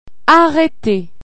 La police   tawmroo-uht
La police   poalees